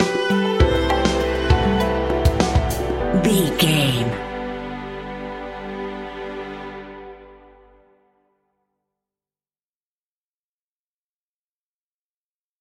Ionian/Major
fun
energetic
uplifting
instrumentals
indie pop rock music
upbeat
groovy
guitars
bass
drums
piano
organ